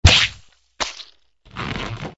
AA_drop_sandbag.ogg